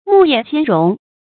暮夜先容 注音： ㄇㄨˋ ㄧㄜˋ ㄒㄧㄢ ㄖㄨㄙˊ 讀音讀法： 意思解釋： 謂事先暗中行賄，以求寬容。